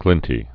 (glĭntē)